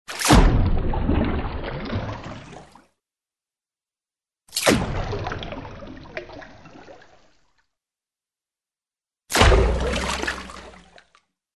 Звуки плавания
Тихий звук погруження у воду